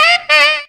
HI LICK.wav